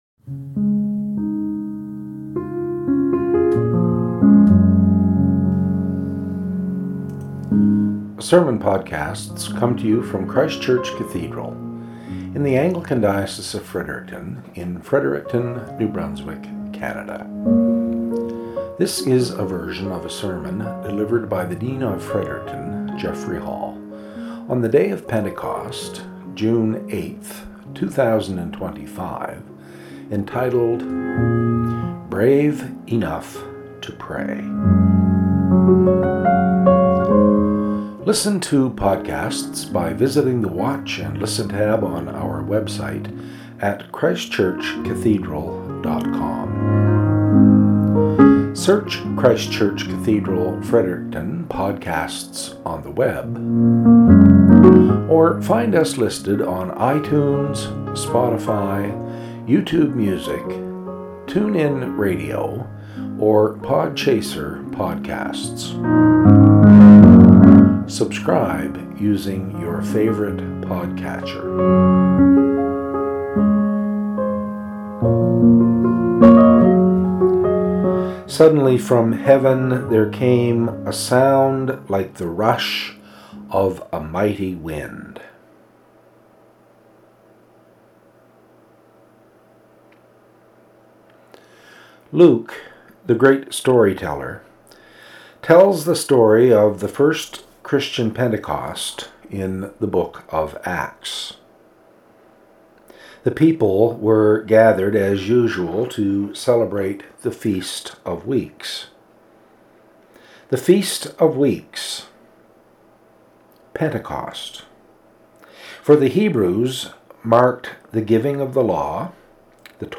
SERMON - "Brave Enough to Pray"